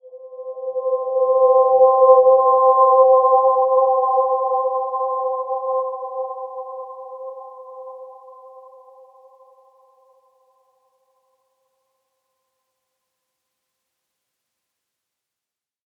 Dreamy-Fifths-C5-mf.wav